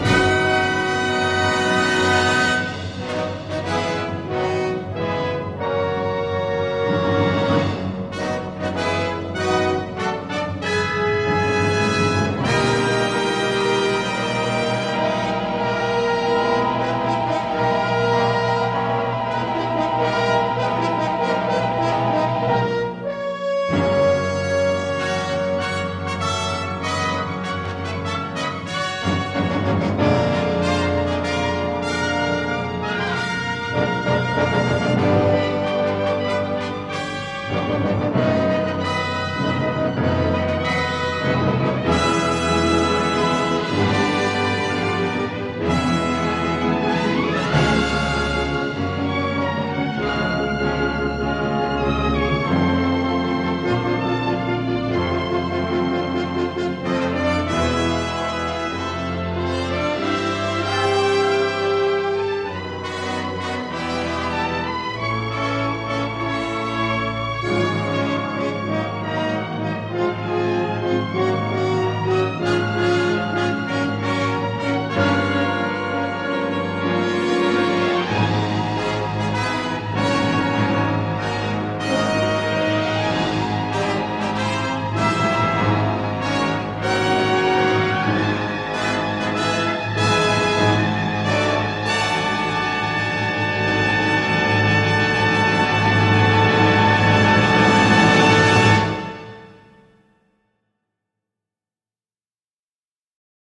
score orchestralo-martial
chouette partition énergique aux accents orientaux marqués
musique martiale, thèmes épiques et sérénades